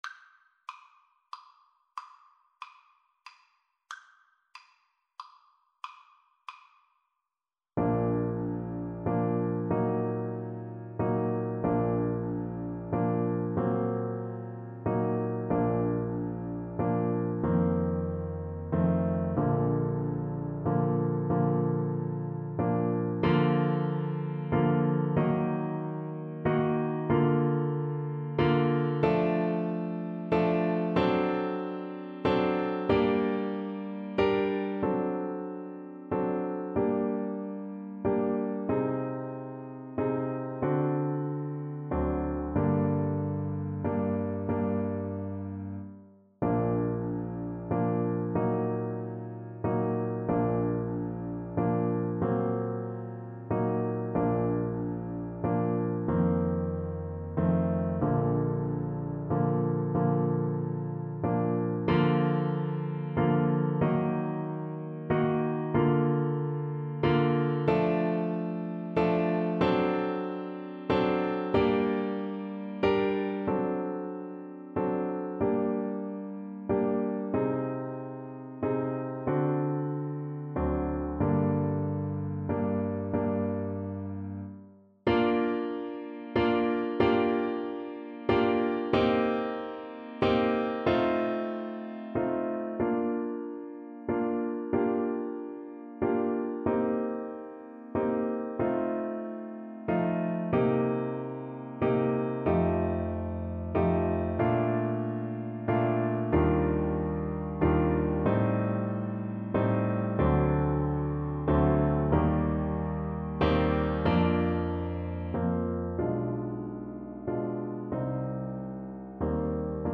Classical Paradis, Maria Theresia von Sicilienne Trumpet version
Play (or use space bar on your keyboard) Pause Music Playalong - Piano Accompaniment Playalong Band Accompaniment not yet available transpose reset tempo print settings full screen
Bb major (Sounding Pitch) C major (Trumpet in Bb) (View more Bb major Music for Trumpet )
6/8 (View more 6/8 Music)
Andantino .=c.45 (View more music marked Andantino)
Classical (View more Classical Trumpet Music)